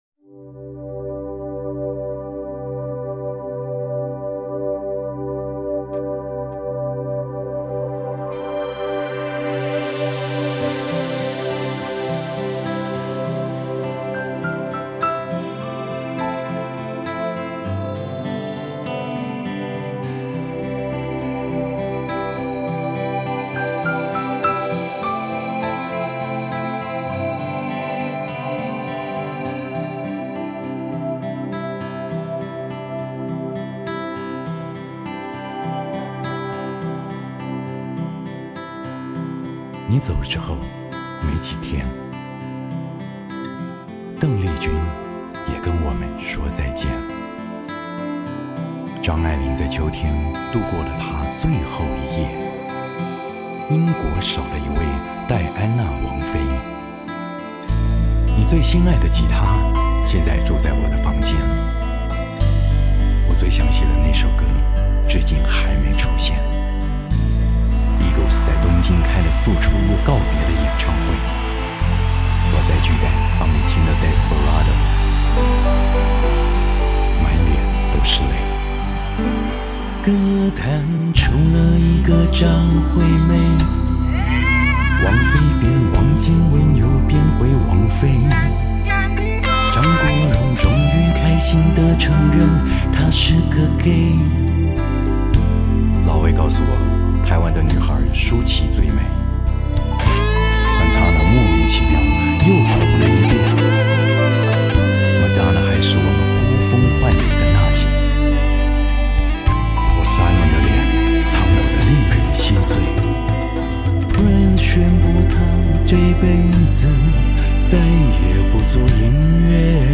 另类歌曲
而他的慢歌则柔情似水，细致地几乎不放过人体的